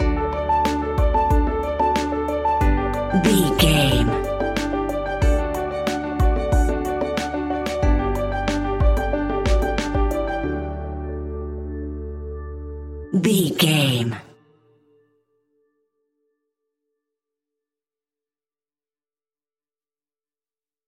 Ionian/Major
D
pop rock
indie pop
energetic
upbeat
groovy
guitars
bass
drums
piano
organ